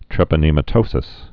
(trĕpə-nēmə-tōsĭs)